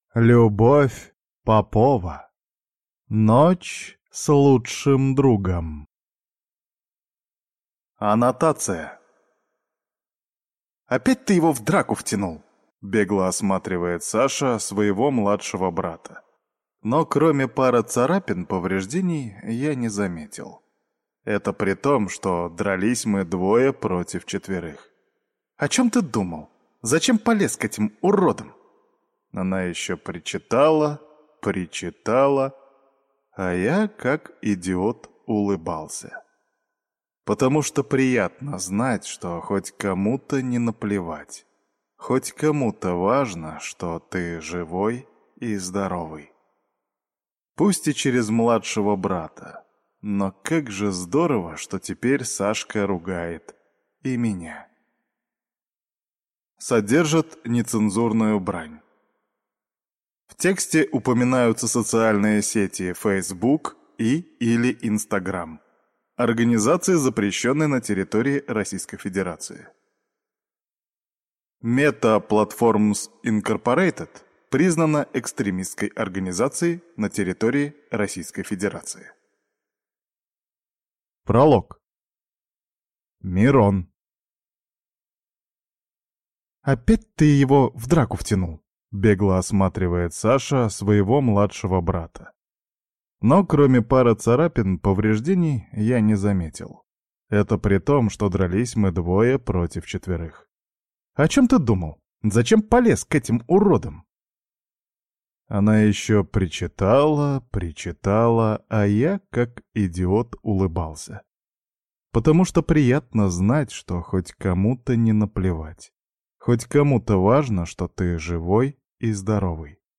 Аудиокнига Ночь с лучшим другом | Библиотека аудиокниг